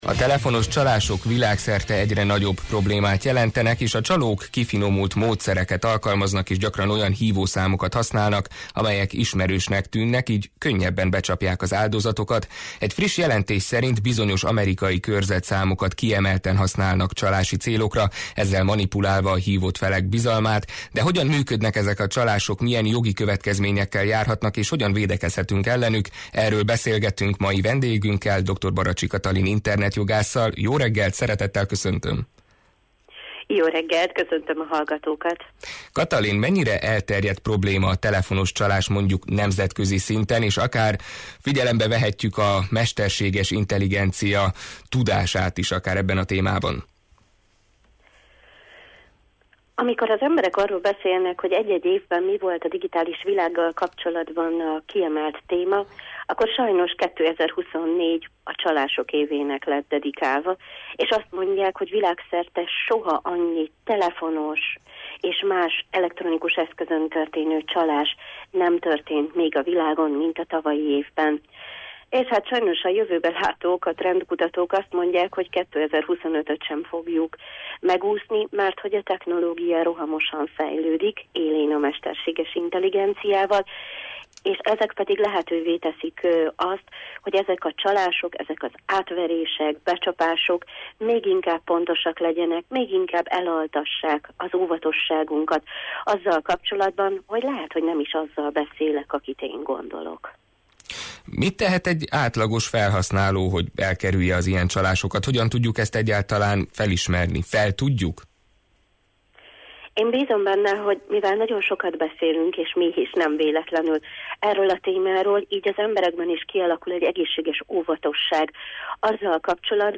De hogyan működnek ezek a csalások, milyen jogi következményekkel járhatnak, és hogyan védekezhetünk ellenük? Erről beszélgettünk mai vendégünkkel